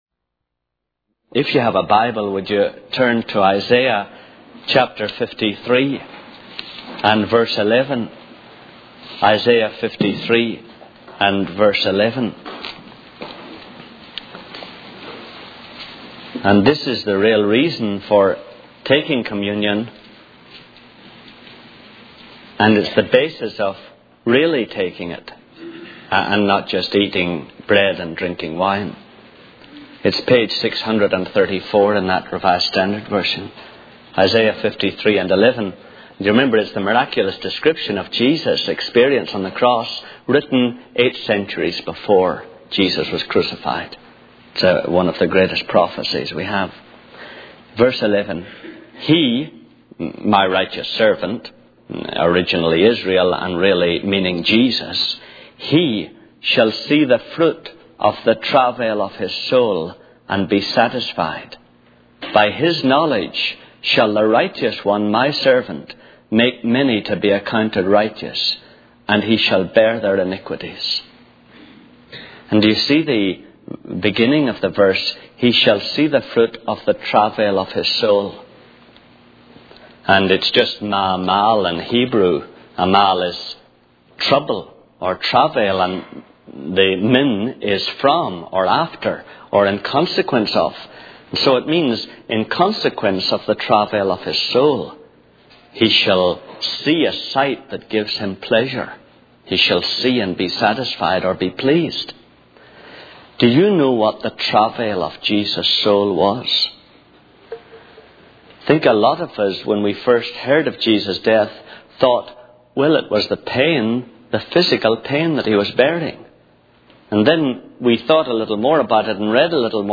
In this sermon, the speaker emphasizes the importance of maintaining a positive mindset, especially in times of suffering.